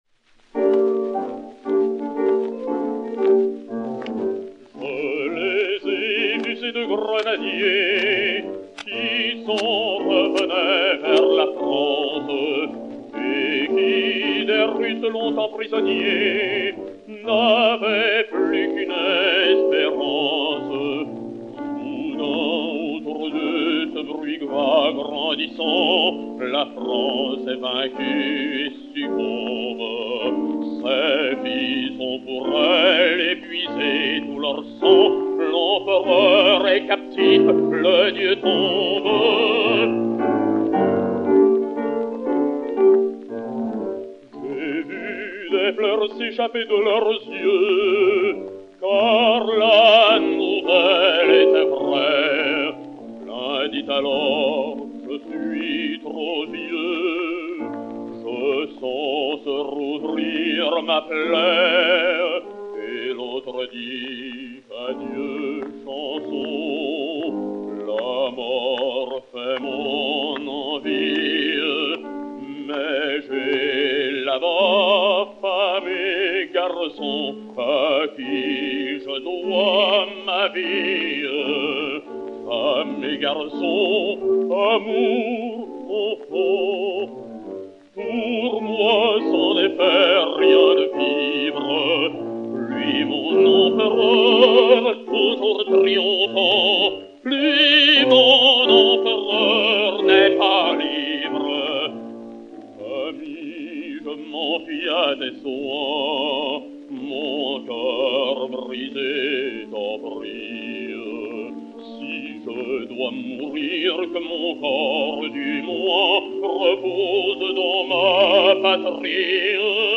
baryton français